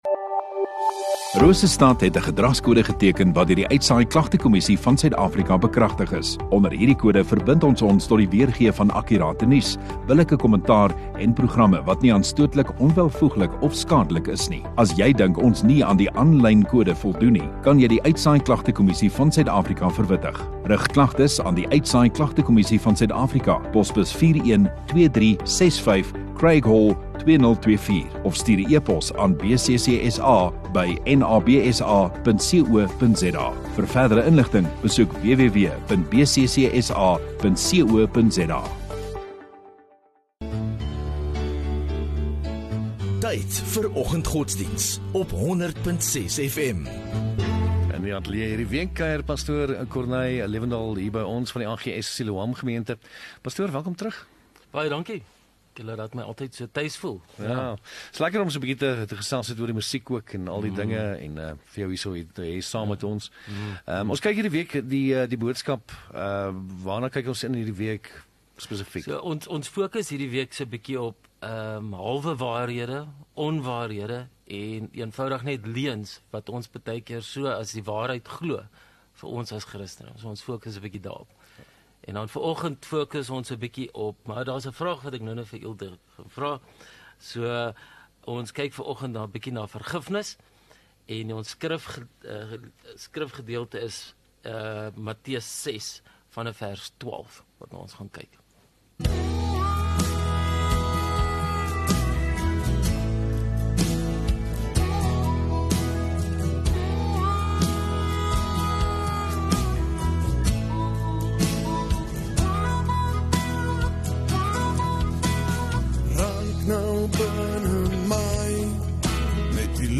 Rosestad Godsdiens